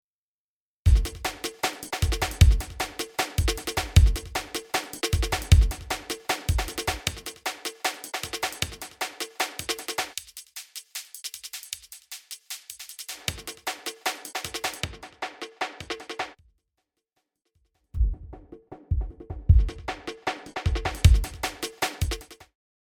An effect that allows you to put out or withdraw the sound of a specific frequency band such as HI / MID / LO, or turn it off completely. 3-BAND is common for DJ applications.